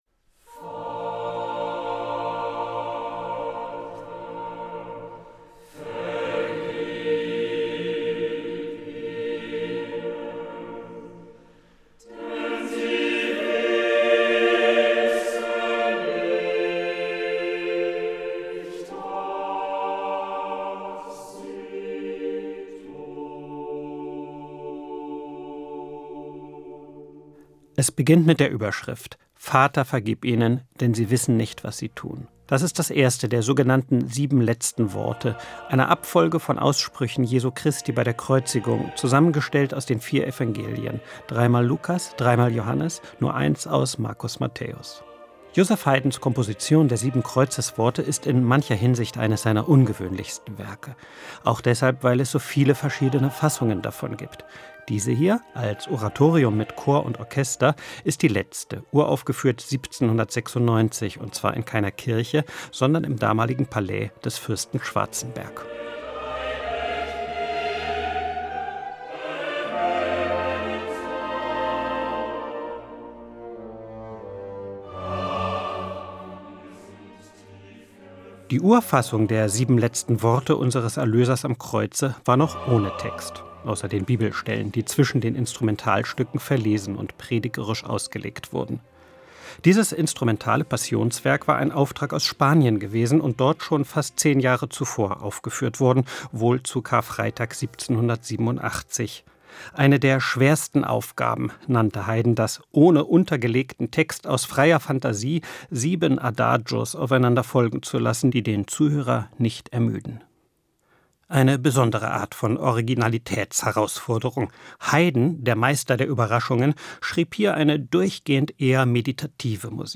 Album-Tipp